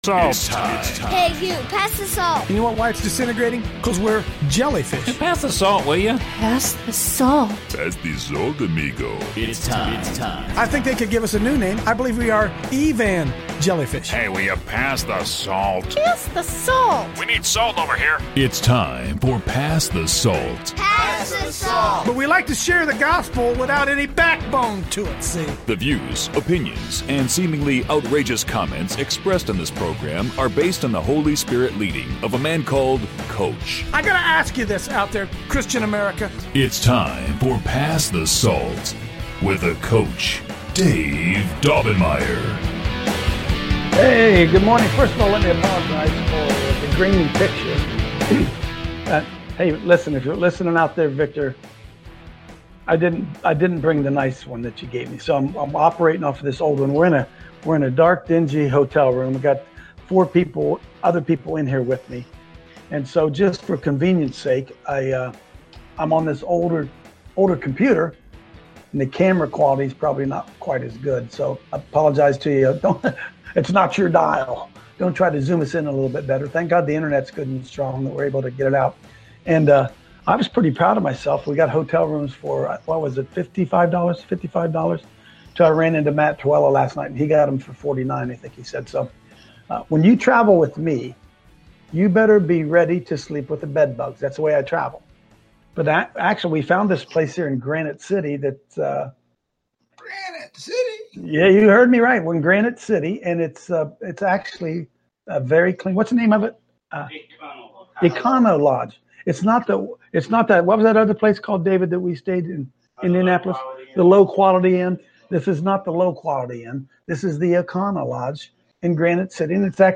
Today’s show is from Urbana 2018, a large Christian missions conference for students, in St. Louis, Missouri.